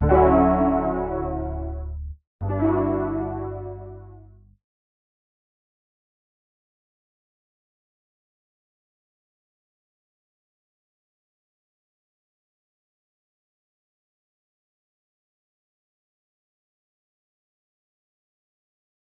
OZ-Sound (Rnb).wav